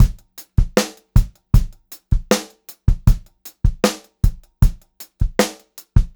78STRBEAT2-R.wav